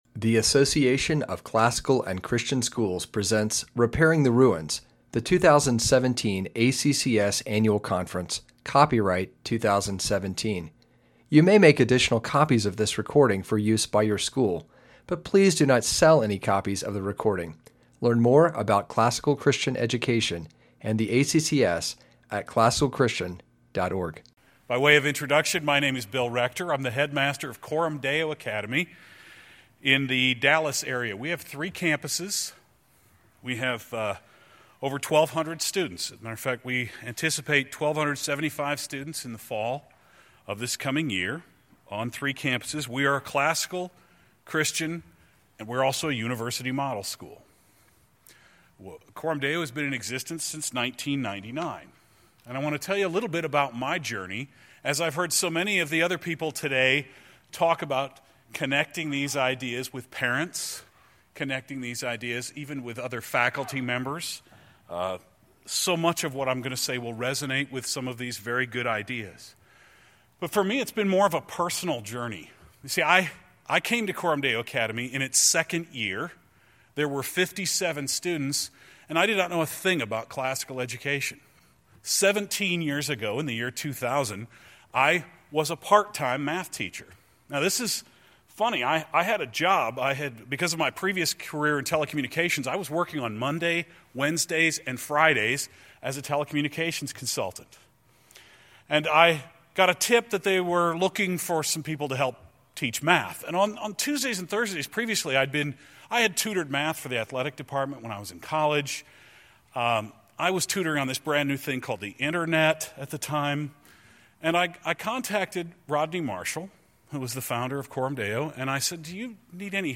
2017 Leaders Day Talk | 0:44:15 | Culture & Faith